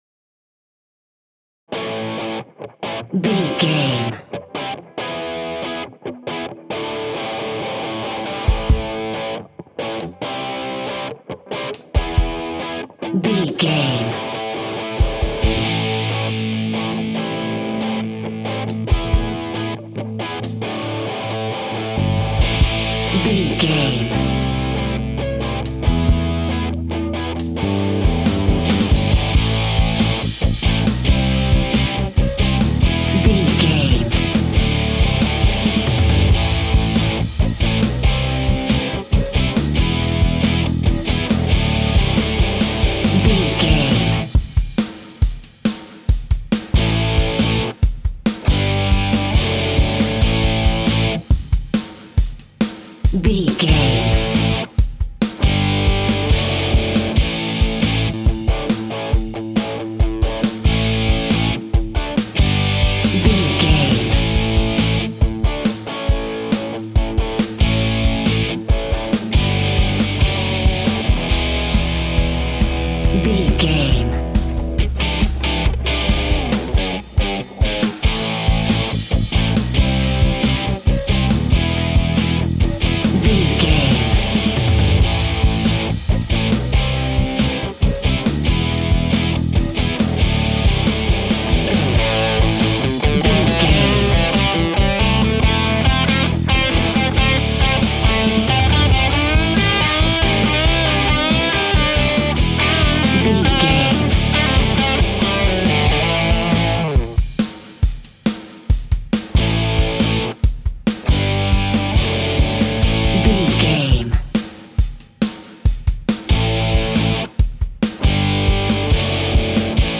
Street Rock in the Eighties.
Ionian/Major
heavy metal
heavy rock
distortion
Instrumental rock
drums
electric guitar
bass guitar
hammond organ
Distorted Guitar
heavy guitars